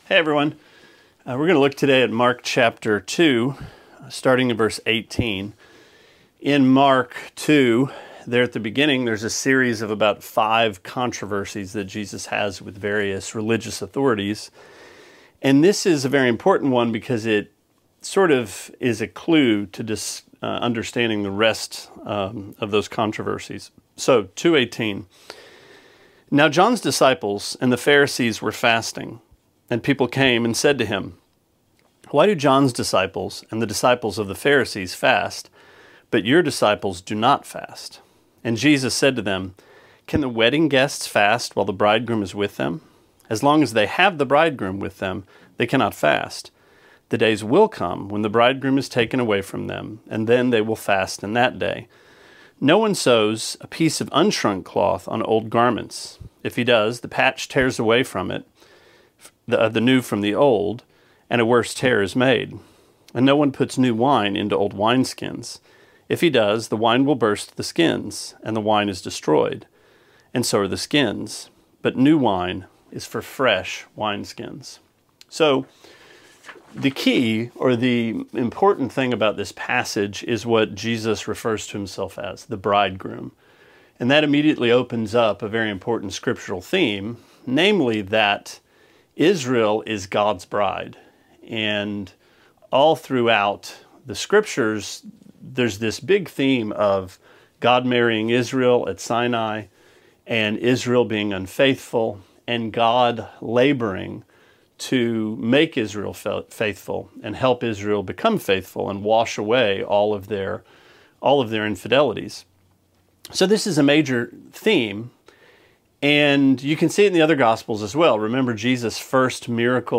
Sermonette 6/19: Mark 2:18-22: A Wedding, New Clothes, & New Wine